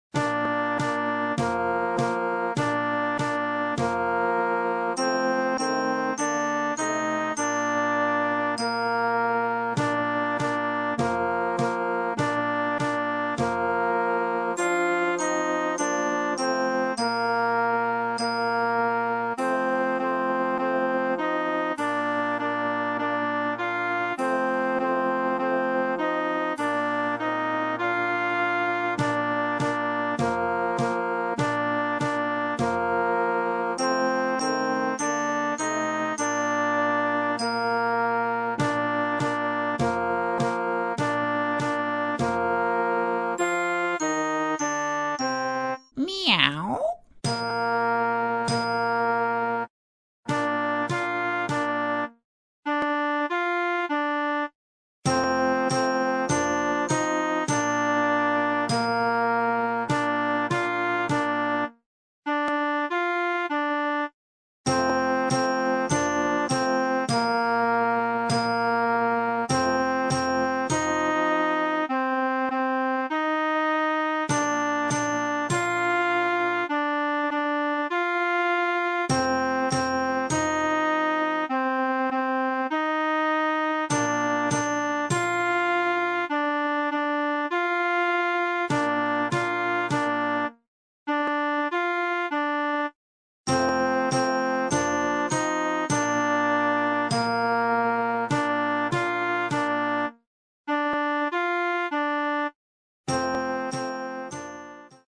Partitions pour ensemble flexible, 3-voix + percussion.